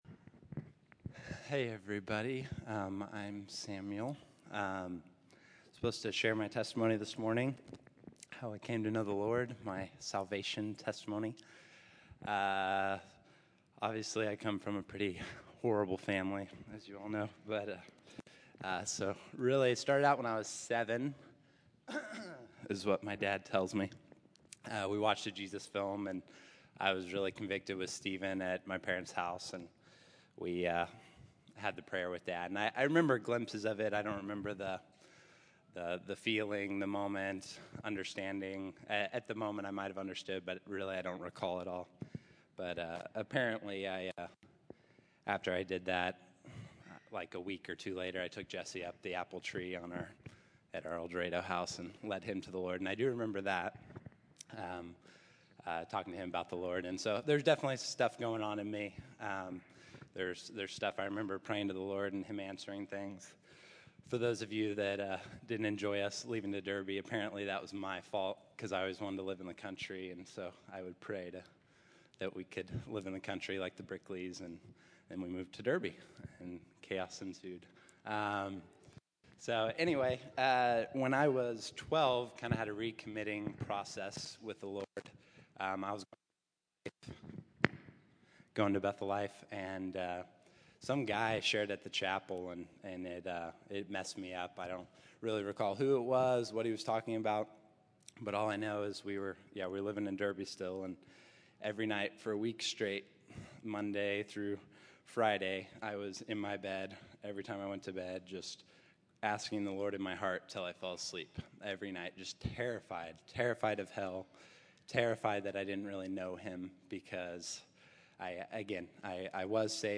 June 08, 2014      Category: Testimonies      |      Location: El Dorado